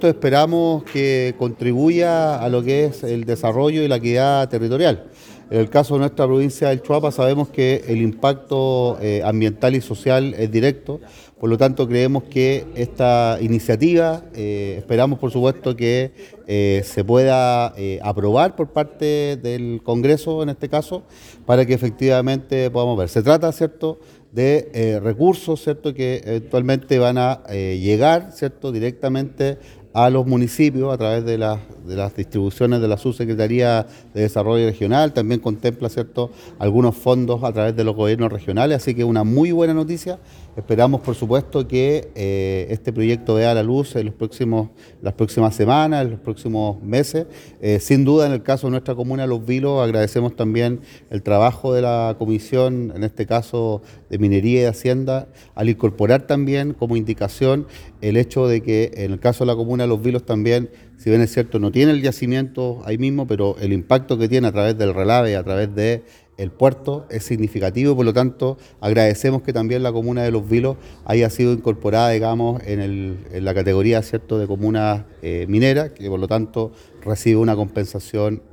Con la presencia de alcaldes, autoridades de Gobierno y ciudadanía de la provincia del Choapa, se realizó este miércoles en Illapel la charla “Beneficios del Royalty Minero para las Comunas de la Región de Coquimbo”, convocada por el senador Daniel Núñez.
Por su parte, el jefe comunal de Los Vilos, Cristian Gross, argumentó que
ALCALDE-CRISTIAN-GROOS-ROYALTY.mp3